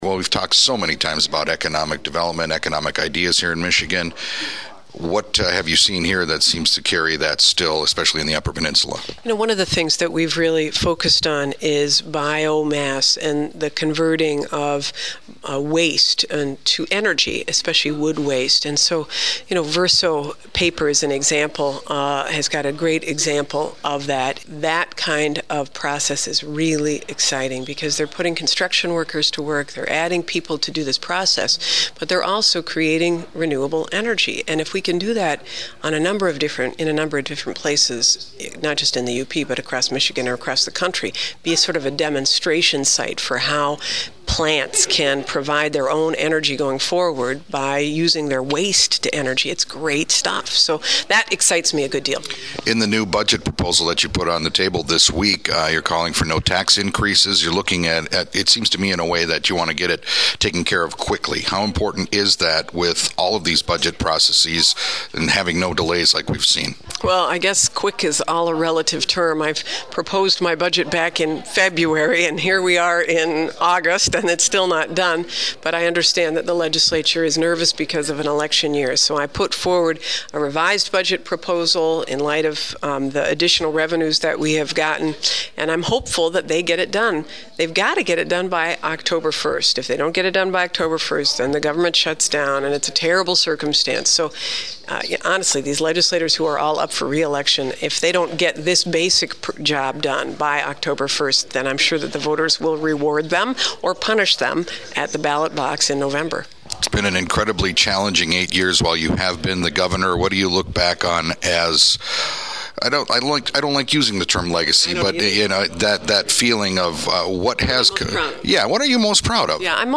During a recent stop at Northern Michigan University in Marquette, Governor Jennifer Granholm gave me a few minutes to before she was whisked off to another event across campus to answer a few questions about some of the economic challenges we are facing in the state and how businesses in the U.P. are responding to those challenges. We also talked about what she is the most proud of in terms of her accomplishments as Michigan’s first female governor and what she is going to miss the most after she leaves office.